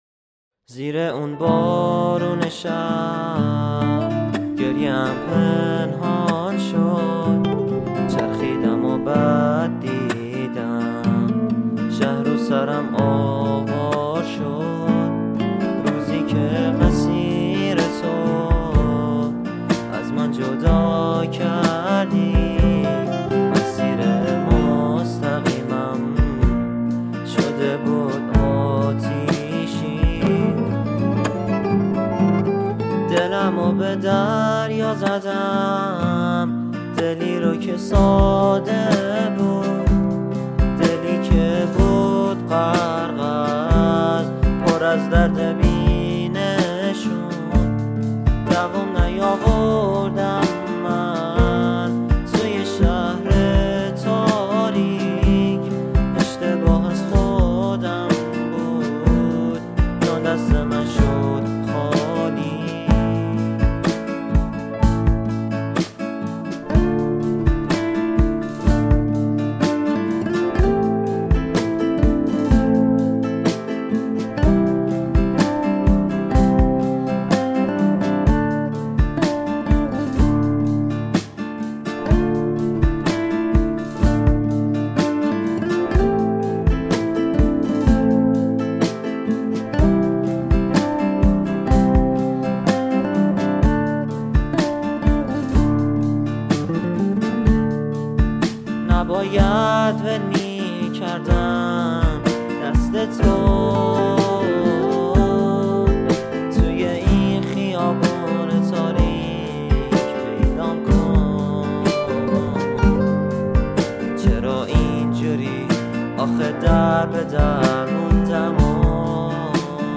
موسیقی
آهنگهای پاپ فارسی